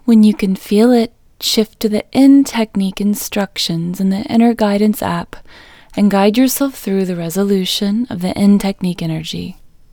LOCATE Short IN English Female 13